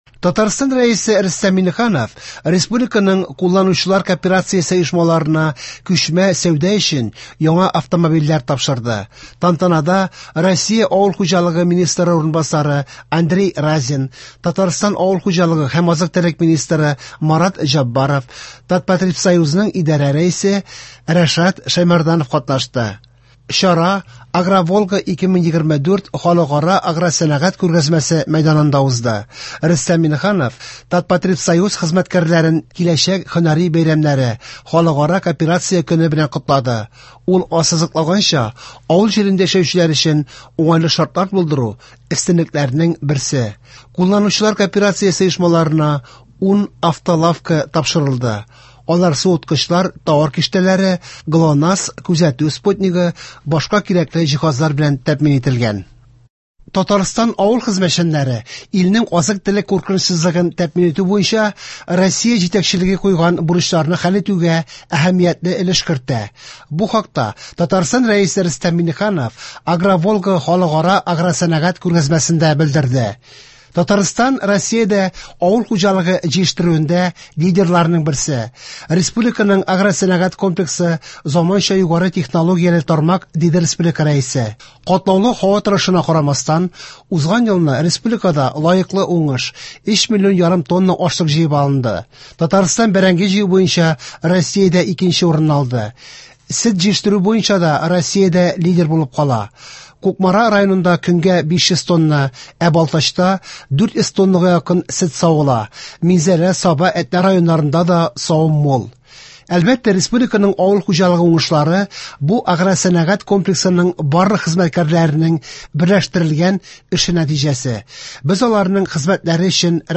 Яңалыклар (05.07.24)